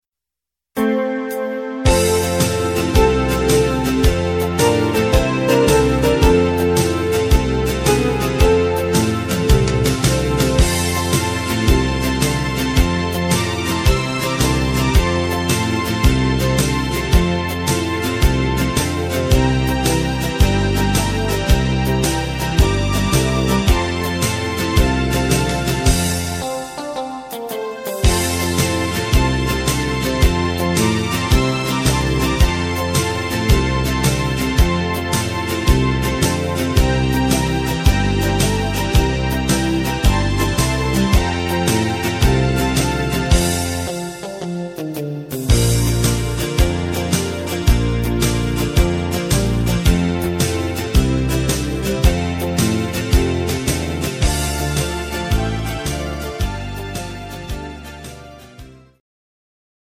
instr. Saxophon